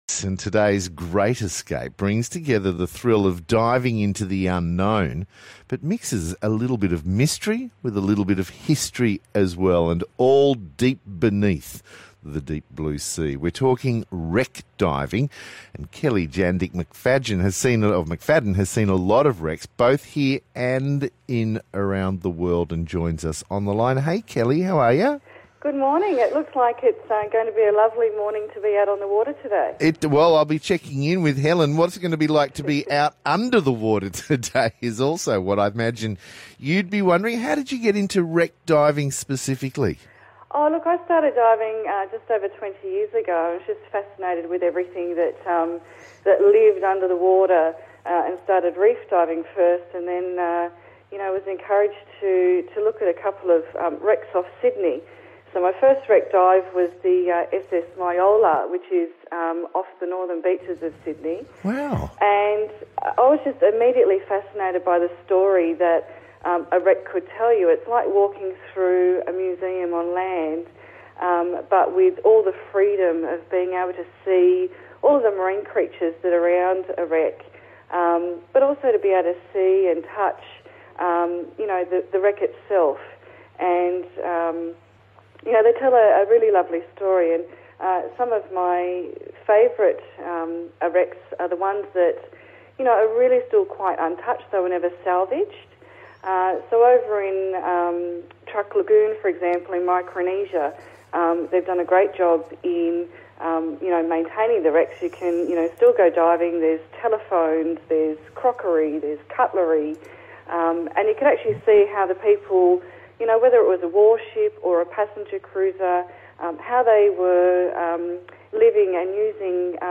A very good interview!